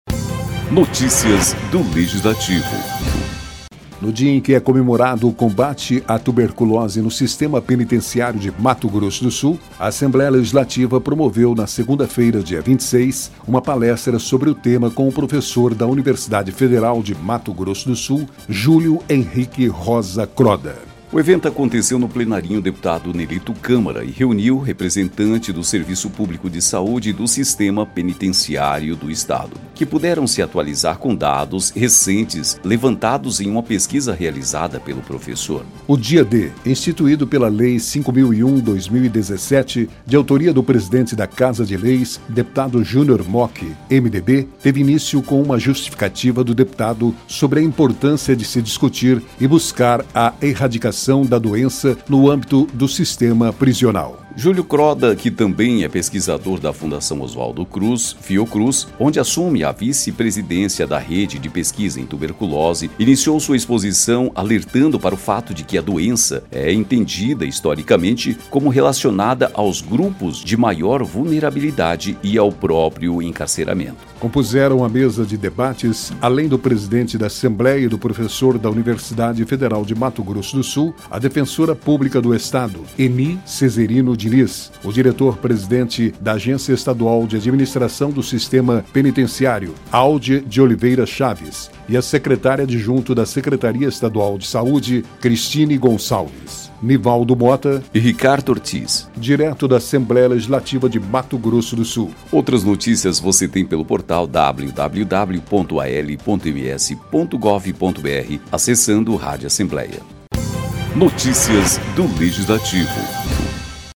Palestra na ALMS apresenta dados sobre combate à tuberculose nos presídios de MS